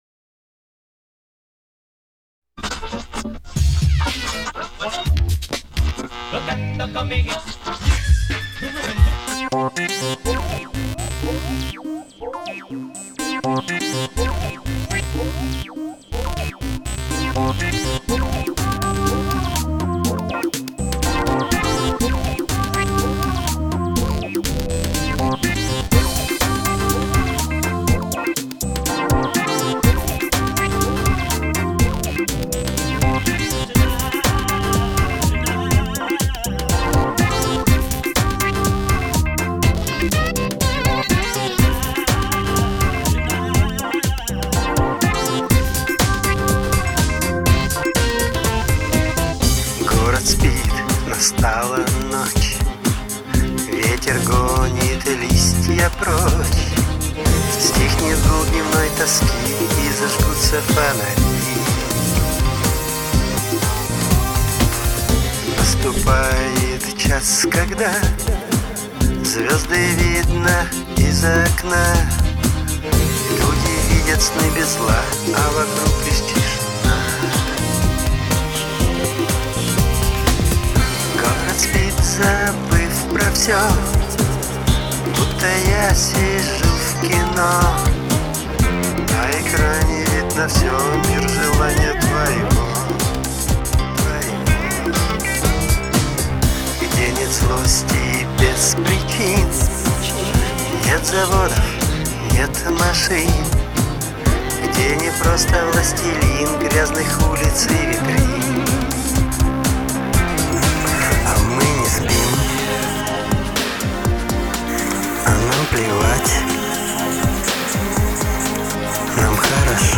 mp3,5805k] Рок